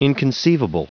Prononciation du mot inconceivable en anglais (fichier audio)
Prononciation du mot : inconceivable